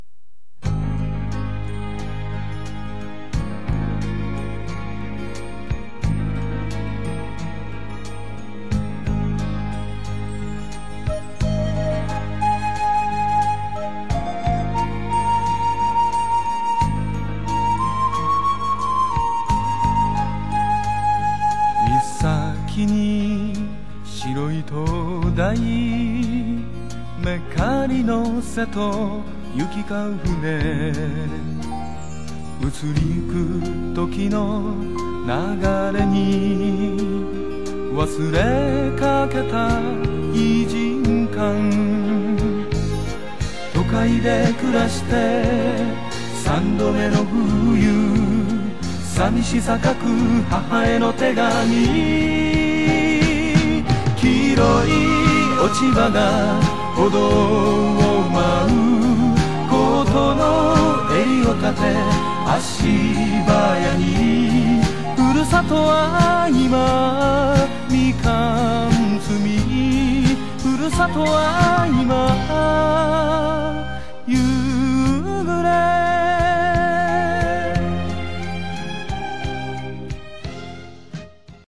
ボーカル＆ベース
ボーカル＆ギター